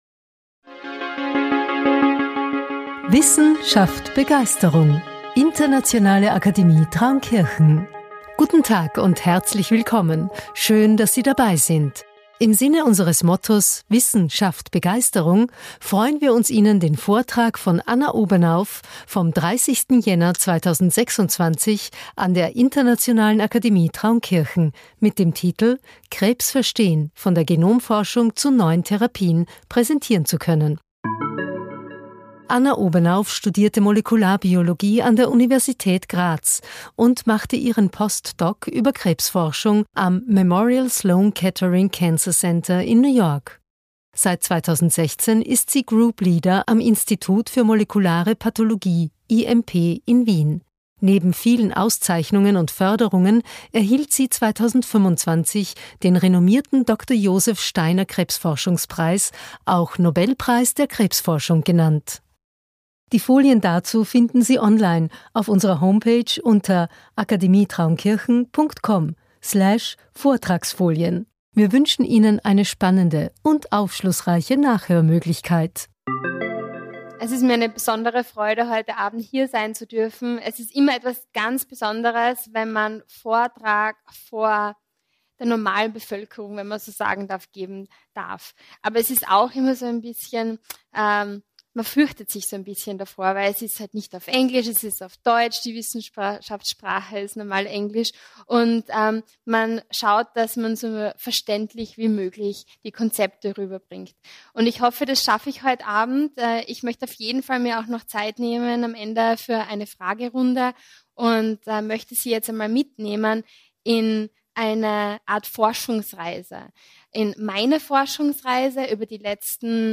Der Vortrag gab einen verständlichen Einblick in aktuelle Entwicklungen und thematisierte, wie kombinierte Behandlungsstrategien die Krebsmedizin der Zukunft prägen könnten.